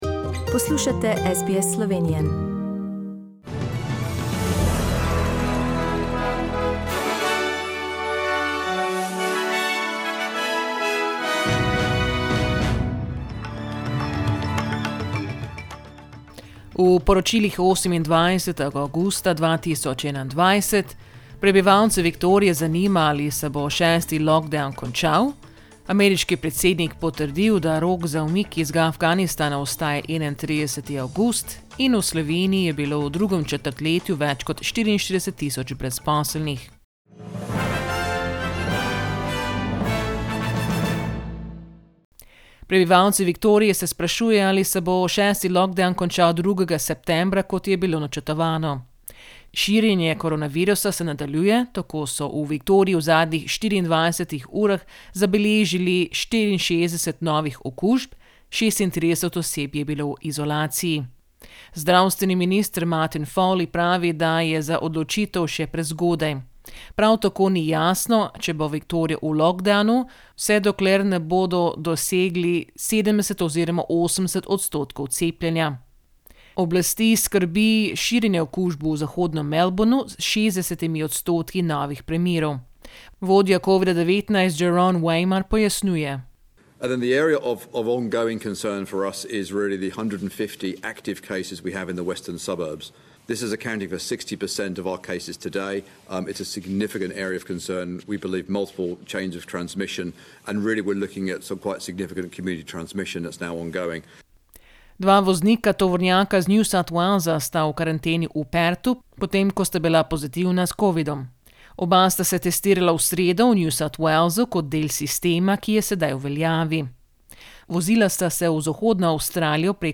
Listen to the latest news headlines in Australia from SBS Slovenian radio.